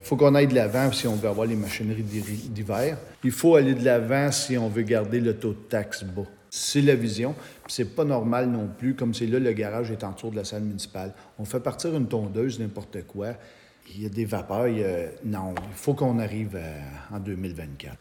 Le maire de Bouchette, Steve Lefebvre, affirme qu’un nouveau garage municipal est nécessaire :